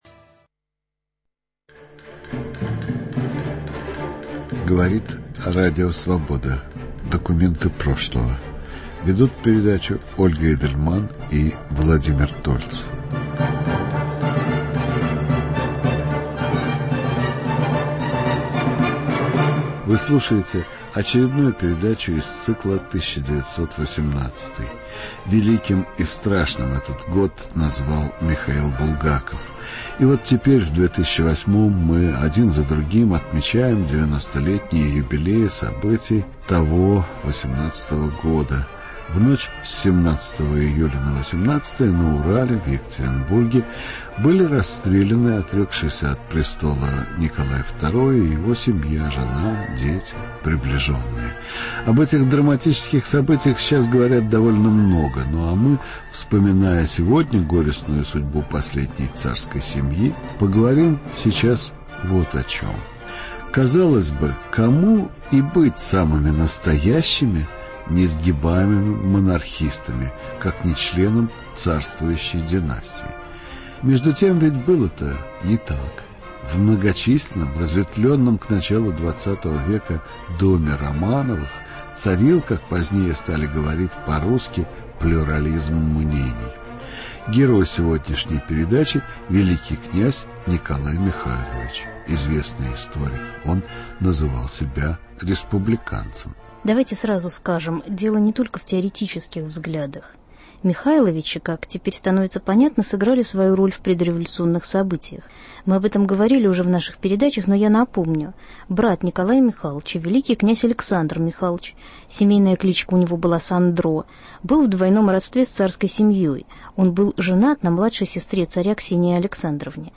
"1918-й": великие князья Сергей и Николай Михайловичи – жертвы красного террора. Эксперт в студии - доктор исторических наук